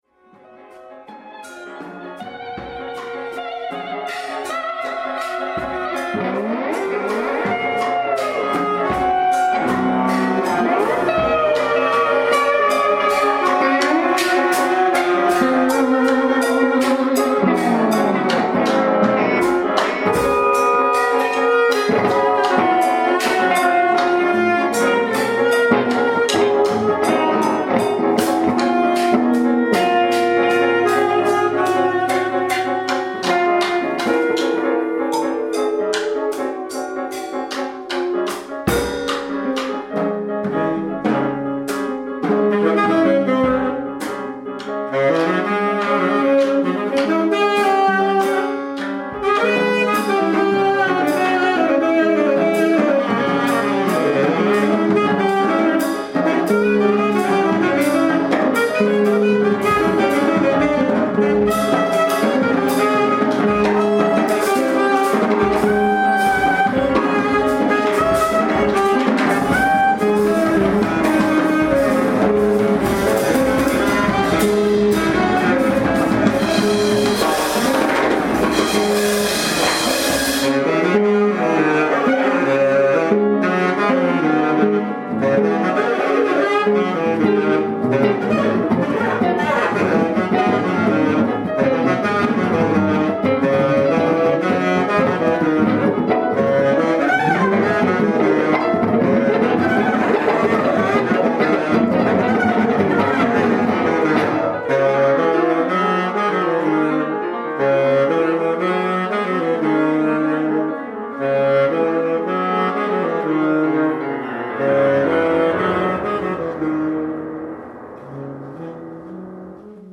Avant-gospel band, focused on spirituals and radical liberation.
Semi-improvisational composition performed by the Trouble Ensemble
Based on repeating musical figures and variations on them, and also on the gospel song I’m So Glad Trouble Don’t Last Always.